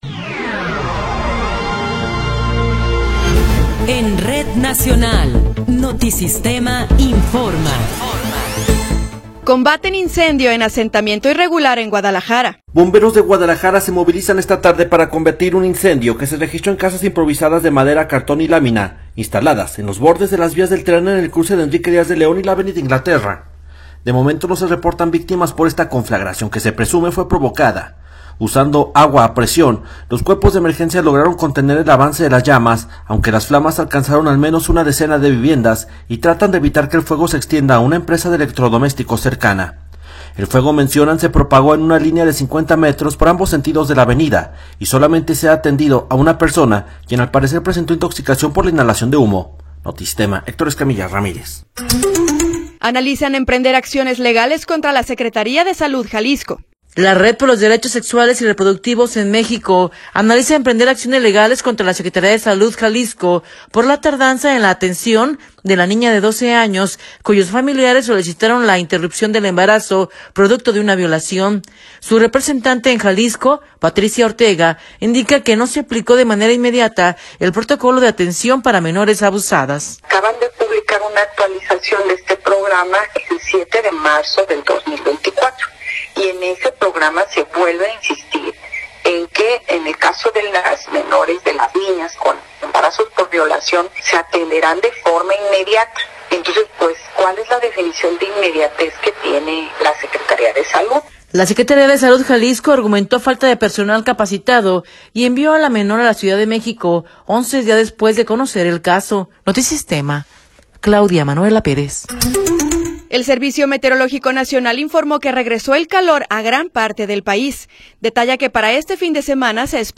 Noticiero 15 hrs. – 25 de Abril de 2024
Resumen informativo Notisistema, la mejor y más completa información cada hora en la hora.